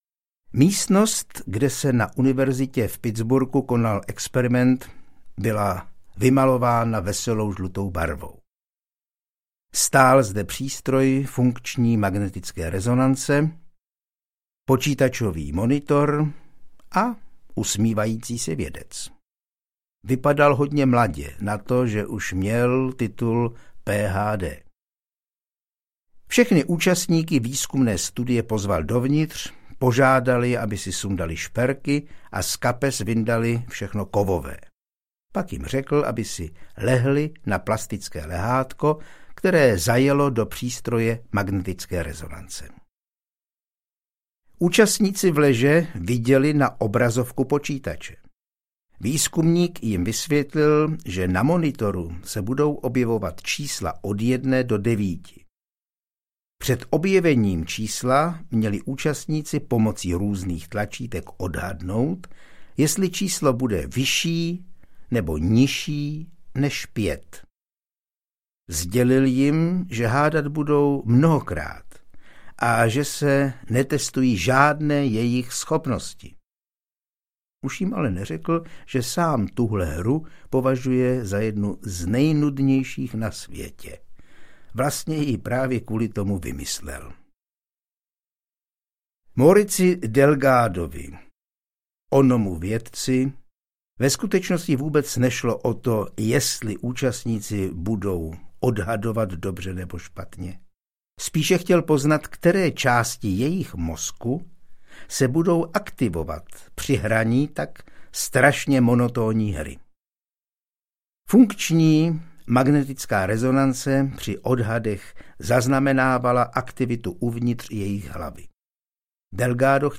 Chytřeji, rychleji, lépe audiokniha
Ukázka z knihy